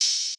{OpenHat} Ends.wav